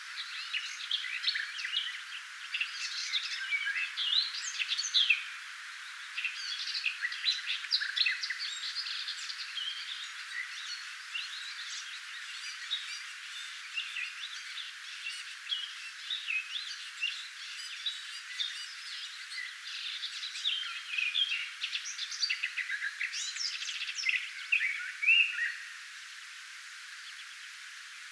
ensi kertaa hiukan lehtokertun visertelylaulua
kahden lyhyen säkeen välissä?
ilmeisesti_hiukan_lehtokertun_visertelylaulua_parin_vaillinaisen_laulusakeen_valissa_todella_erikoista.mp3